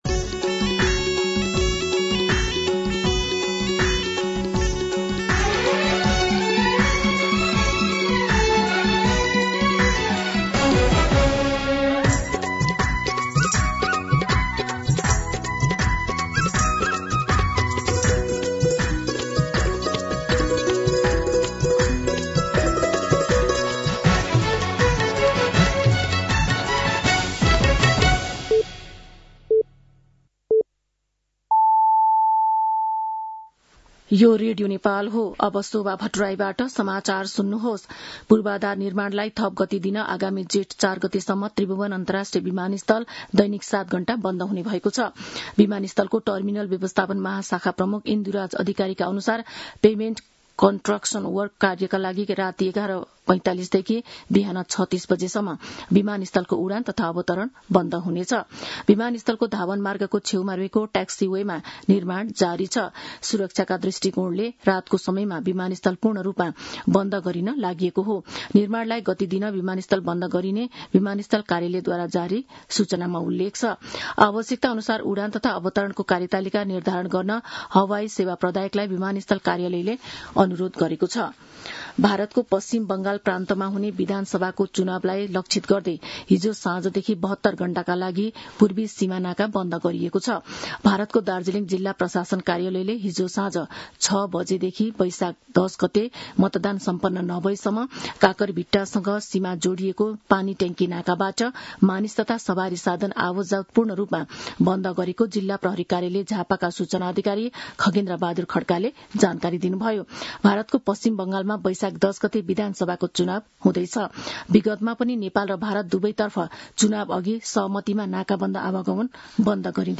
मध्यान्ह १२ बजेको नेपाली समाचार : ८ वैशाख , २०८३